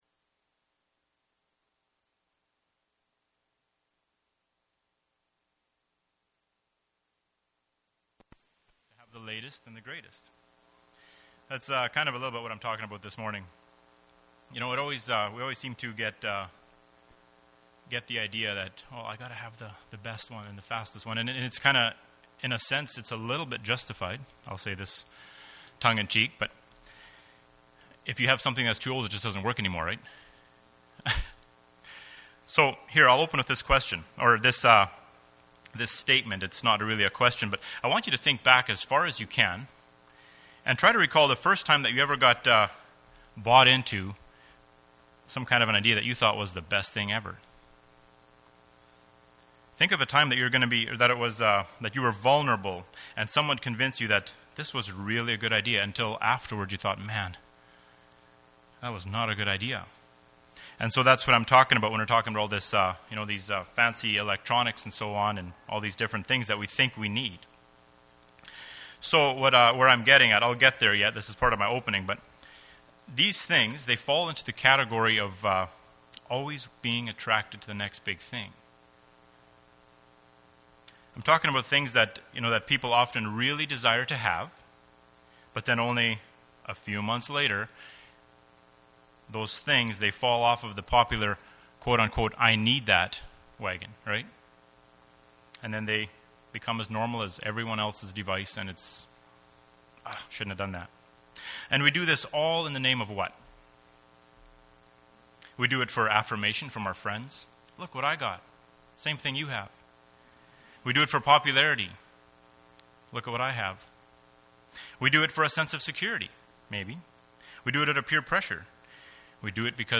Service Type: Adult Bible Fellowship